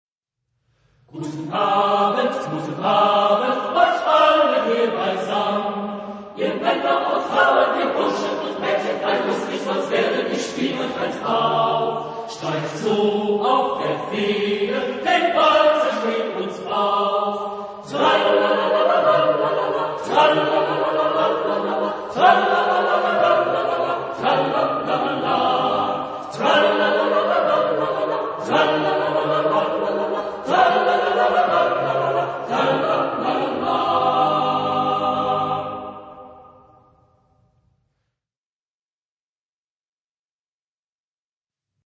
Genre-Style-Form: Folk music ; ballet ; Partsong ; Secular
Type of Choir: SATB  (4 mixed voices )
Tonality: G major